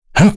Kain-Vox_Jump.wav